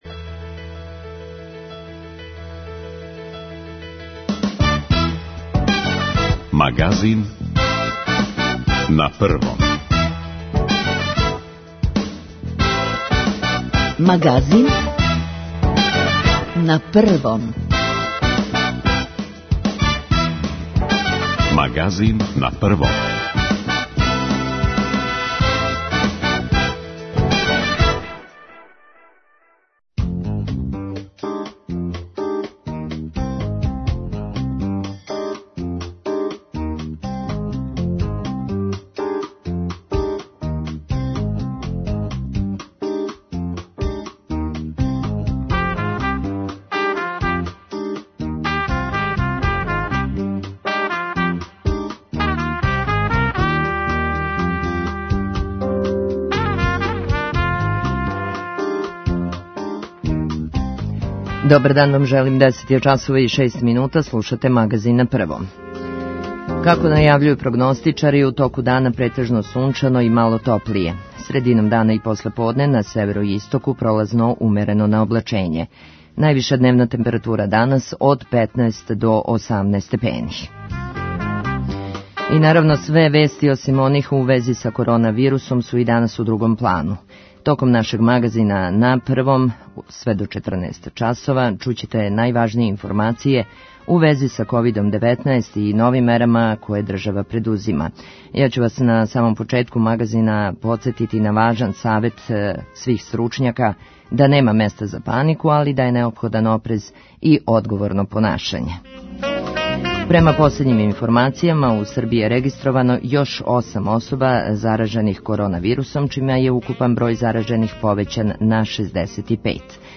У данашњој емисији проверавамо колике су залихе крви и колико је у новонасталој ситуацији безбедно давати крв. Такође, проверавамо како су се организовале адвокатске коморе и судови а како привреда . Гост нам је и повереник за информације од јавног значаја Милан Мариновић.